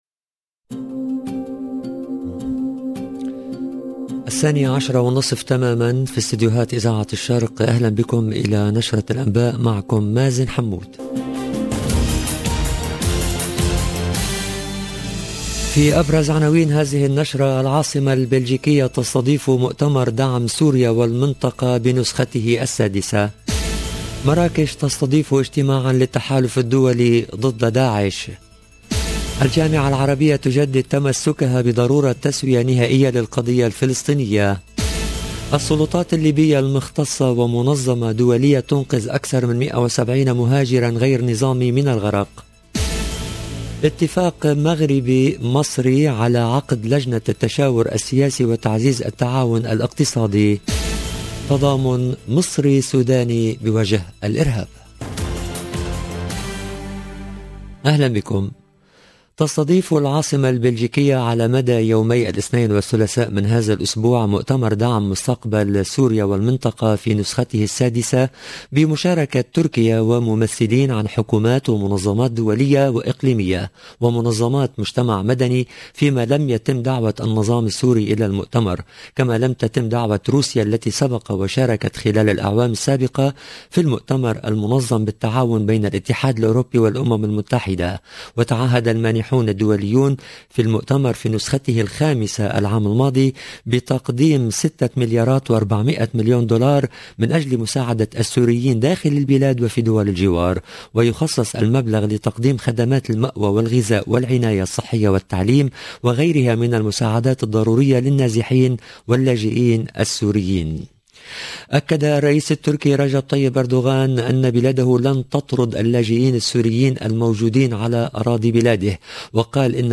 LE JOURNAL EN LANGUE ARABE DE MIDI 30 DU 10/05/22